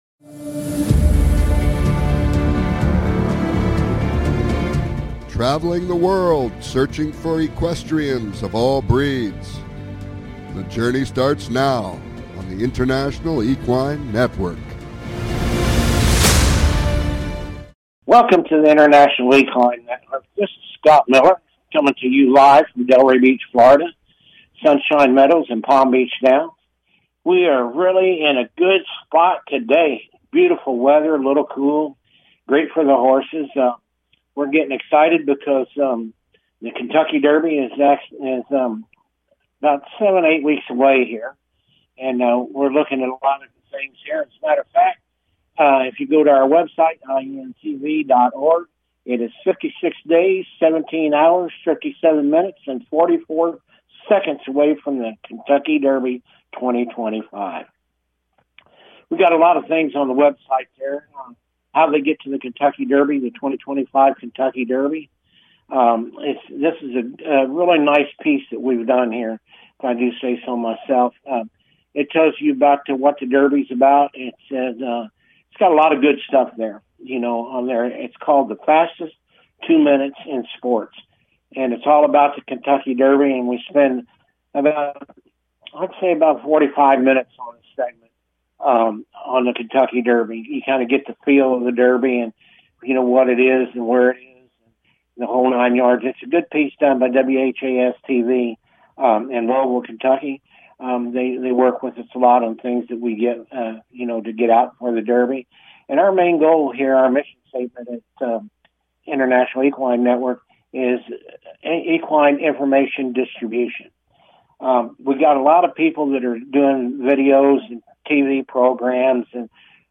Calls-ins are encouraged!